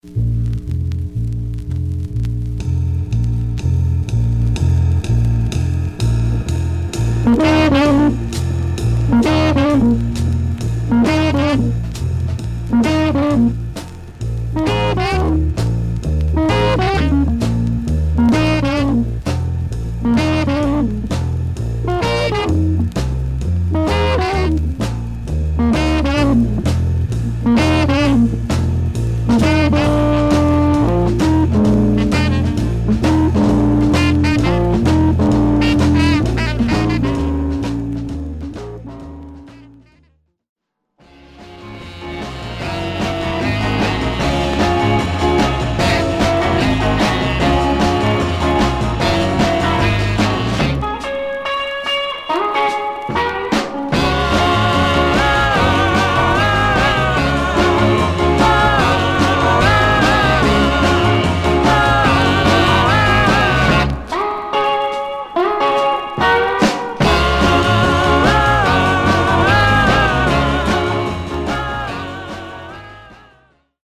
Stereo/mono Mono
R&B Instrumental